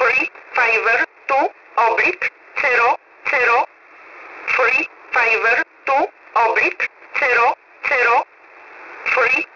Mode: USB Frequency: 6849.00kHz